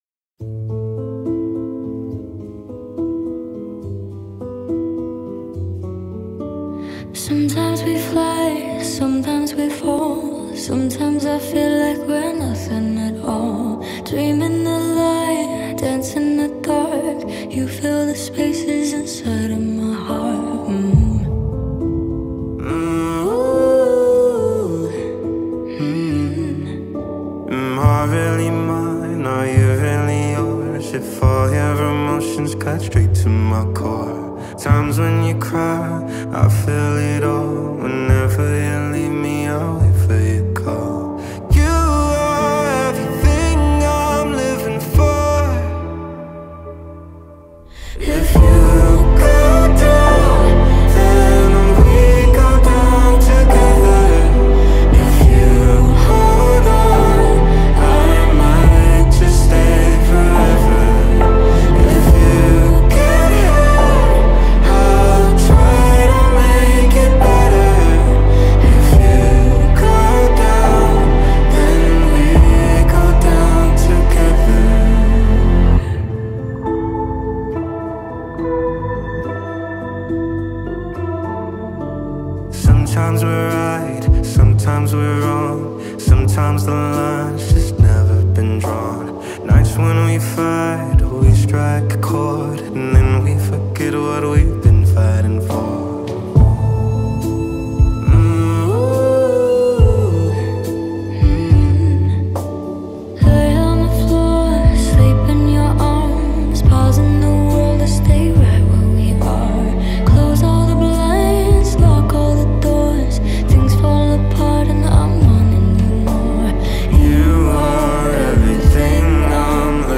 یک آهنگ عاشقانه آرام و تاریک